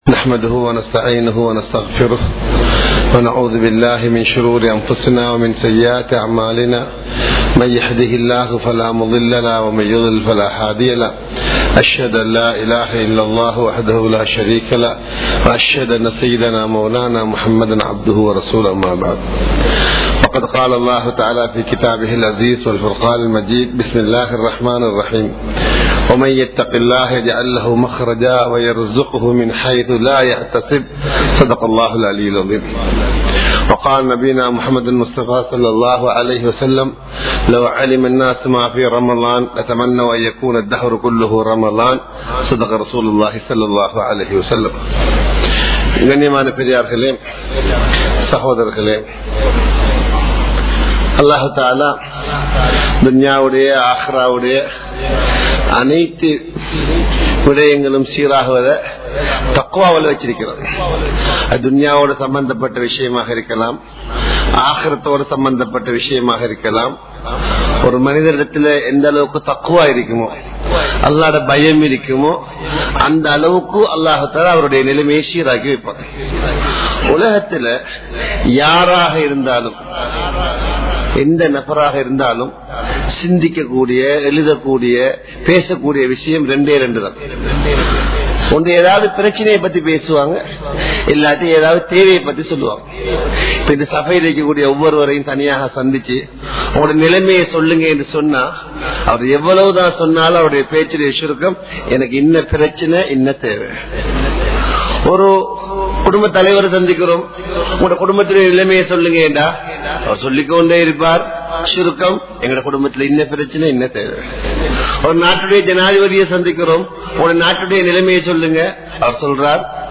Colombo 06, Mayura Place, Muhiyadeen Jumua Masjith